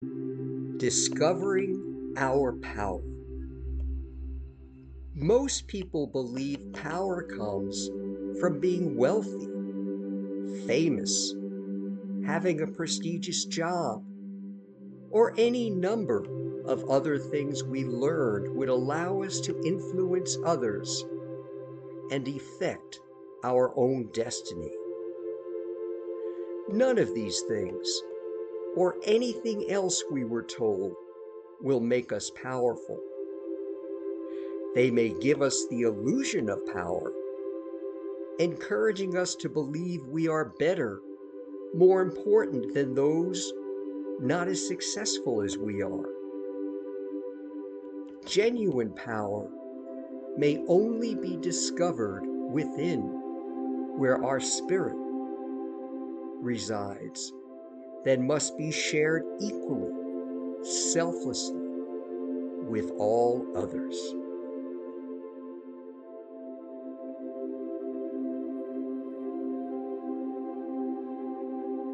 An audio Spiritual Reflection: